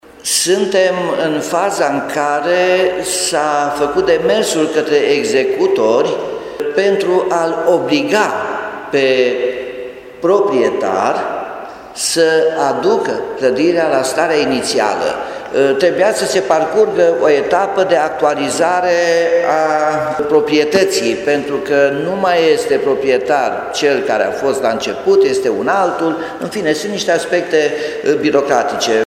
Potrivit primarului Nicolae Robu, demersurile făcute de primărie în urma sentinței sunt doar birocratice.